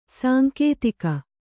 ♪ sānkētika